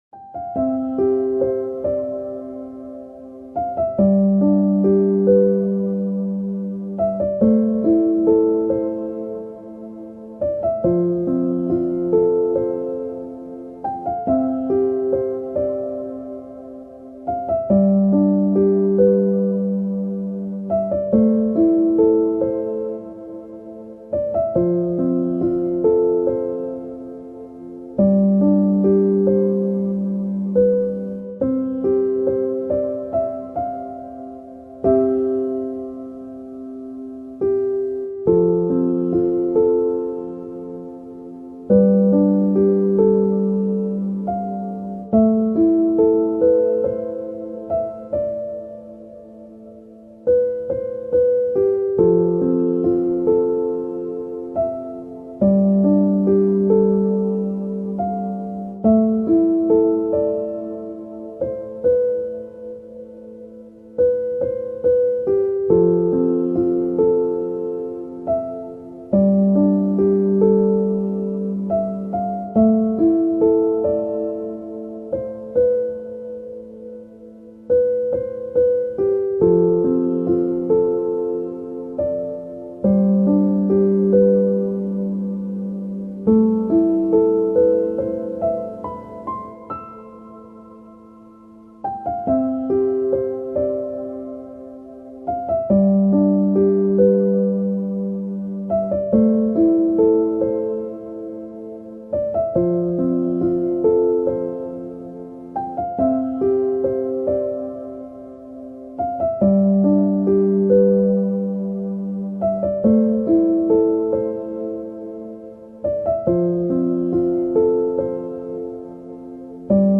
Leichter Regen-Vogelfrieden: Stimmen voller entspannender Ruhe
Naturgeräusche